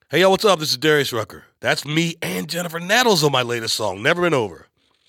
LINER-Darius-Rucker-Never-Been-Over-with-Jennifer-Nettles.mp3